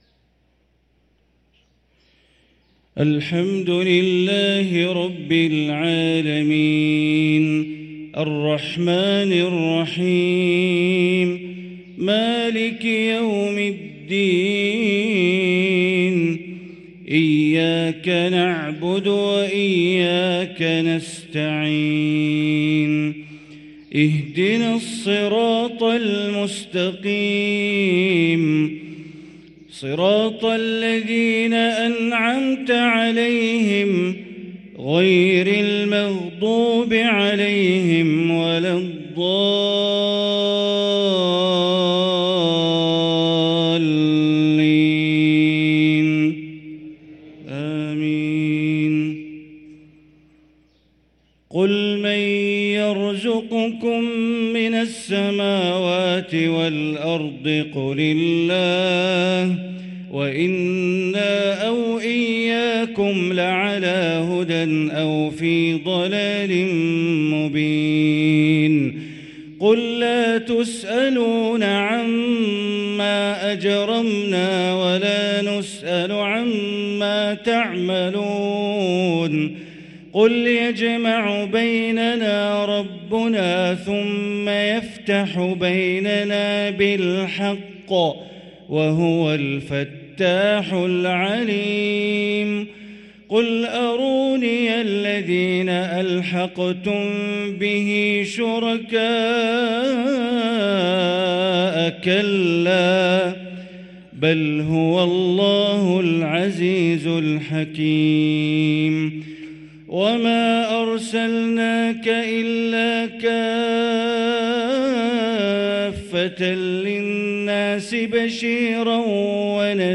صلاة العشاء للقارئ بندر بليلة 4 شعبان 1444 هـ
تِلَاوَات الْحَرَمَيْن .